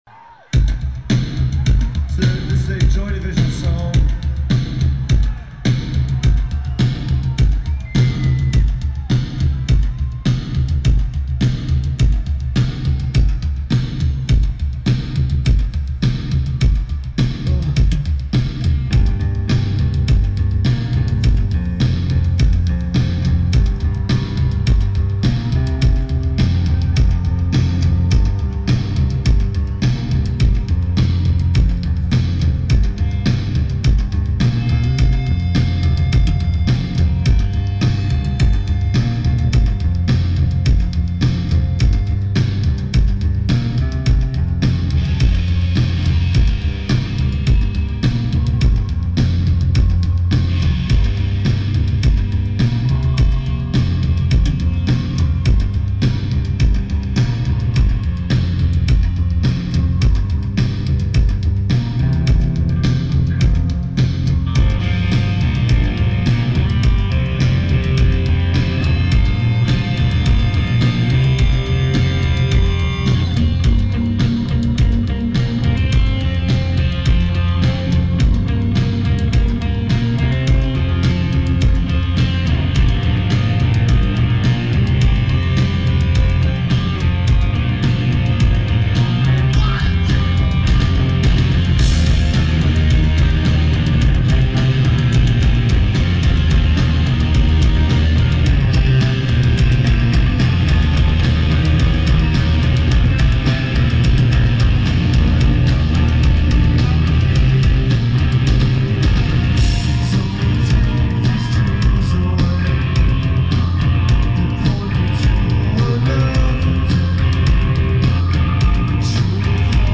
Target Center
Also a pretty extensive encore break here.